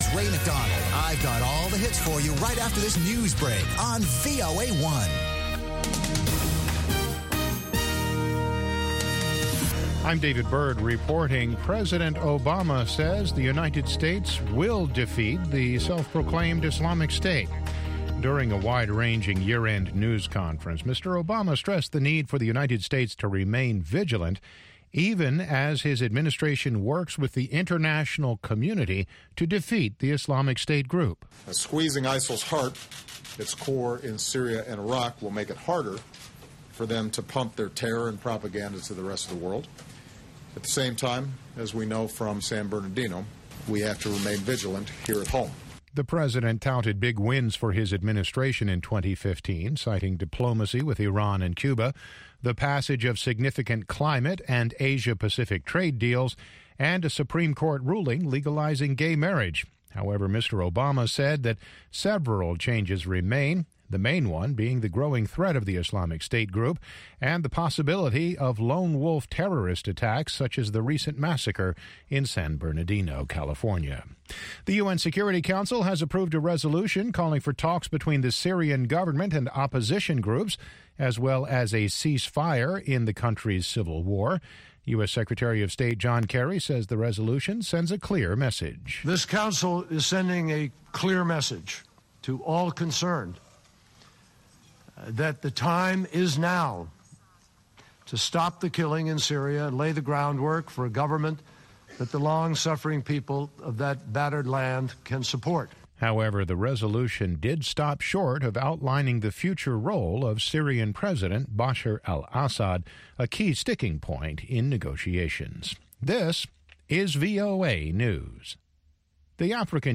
N’dombolo
Benga
African Hip Hop
pan-African music